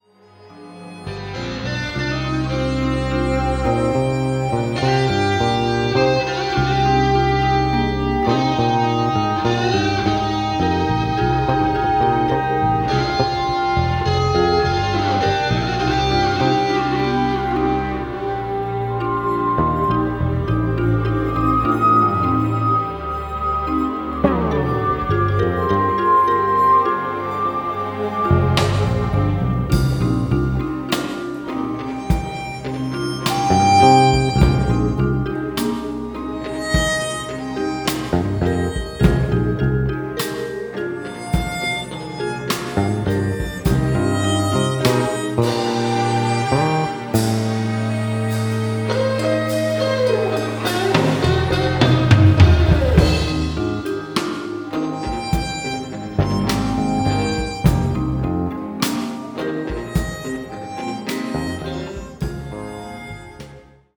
banjo
harmonica